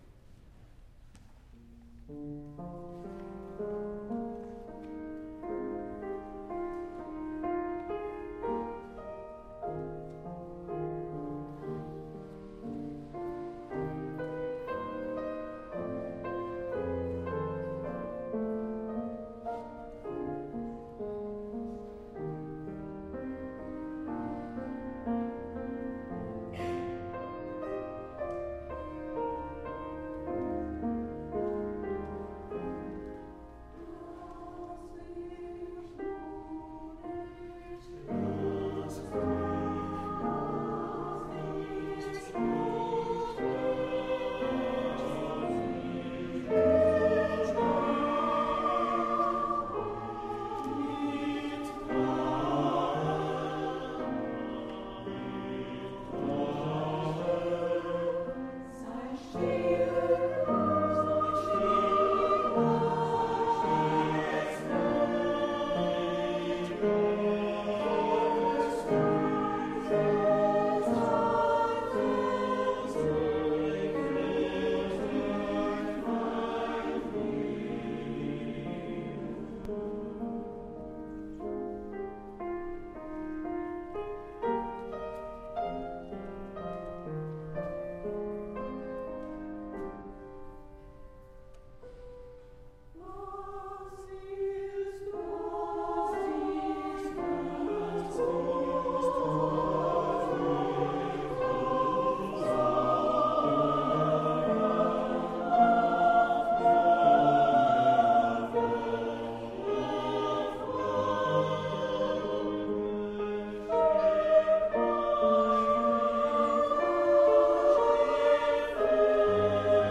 Extraits du concert
6-Geistliches-Lied-opus-30-Brahms-SATB.mp3